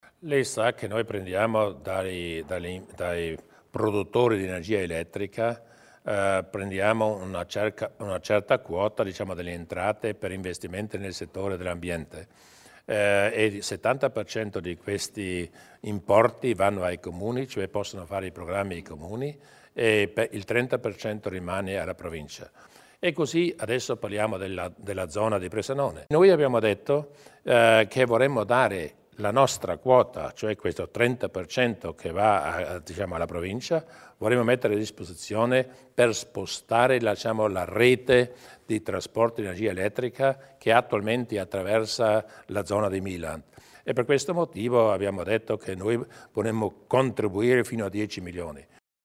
Il Presidente Durnwalder spiega gli interventi a favore dei residenti nel quartiere di Milland